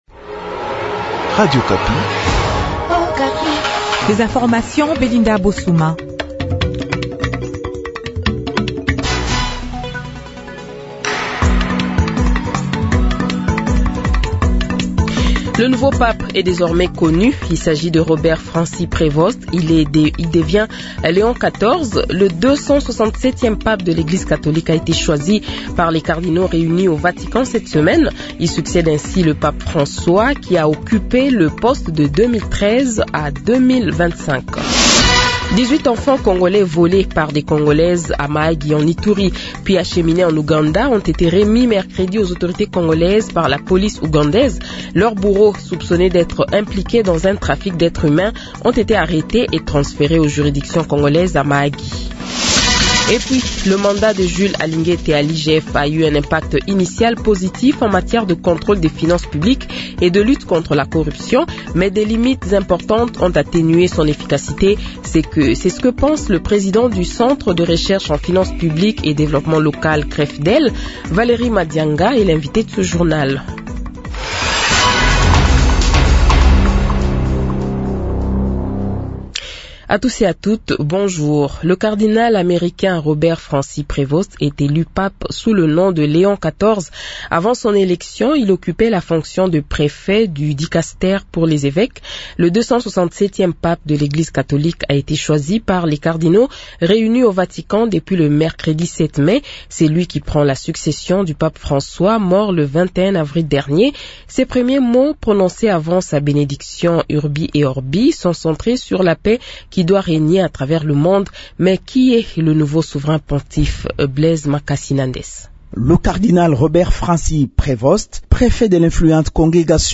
Le Journal de 8h, 09 Mai 2025 :